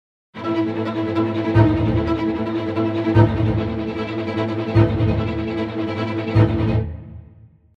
keyswitch sur notes répétées dans un background de cordes pour changer se « sample » et et de poussé/tiré de l’archet dans le but de donner de la vie aux lignes musicales :